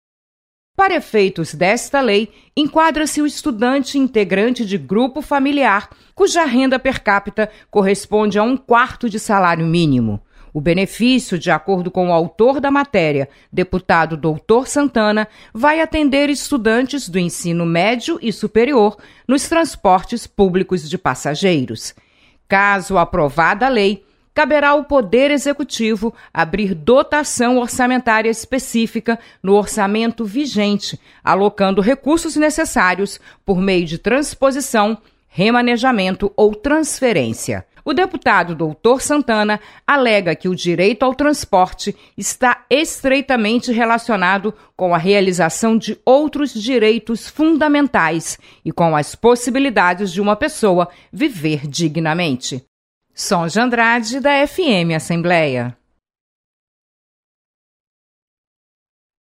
Projeto autoriza o Governo Estadual a criar programa passe livre para estudantes de baixa renda. Repórter